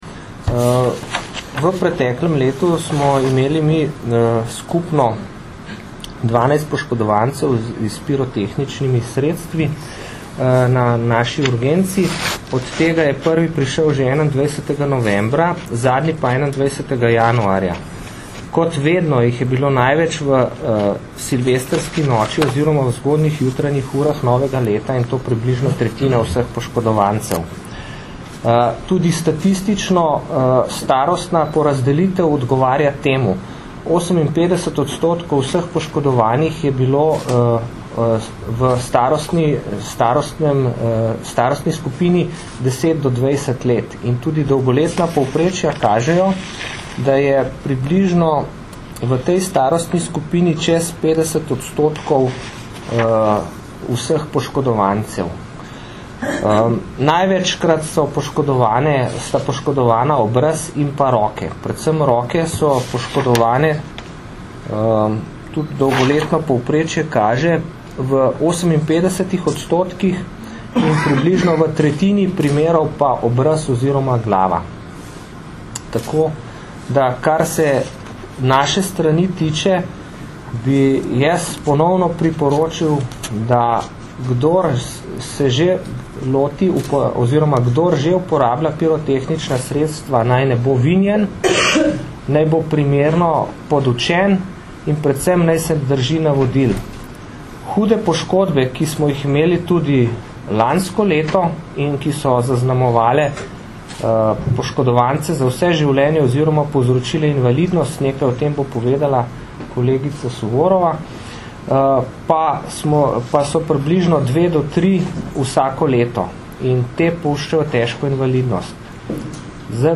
Zvočni zapis izjave